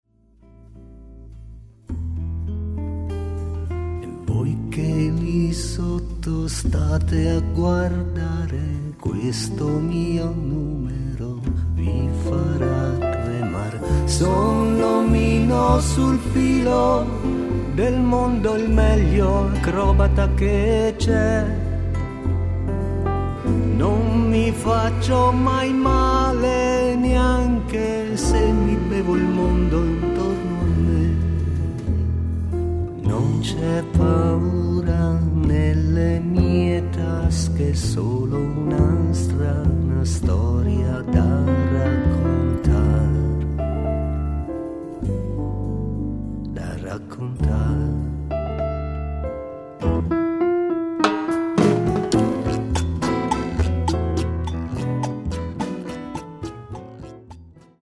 fisarmonica